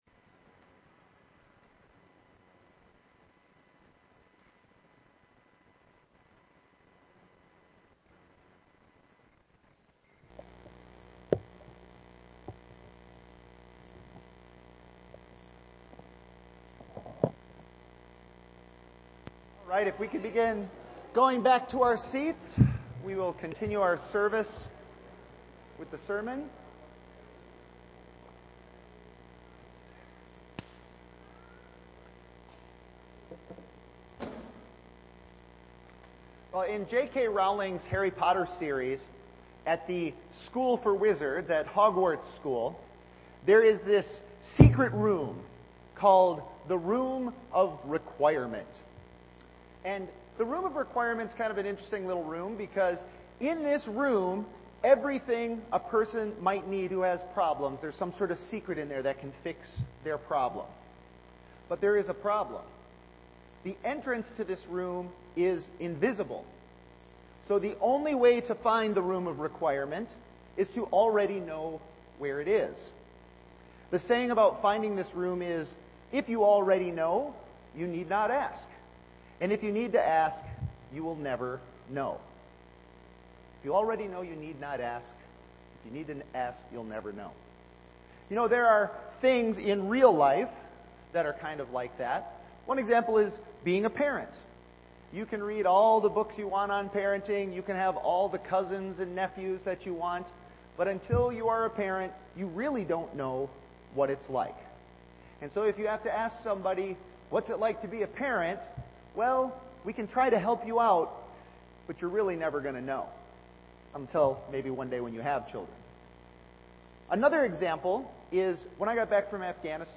John 11:1-16 Service Type: Sunday Worship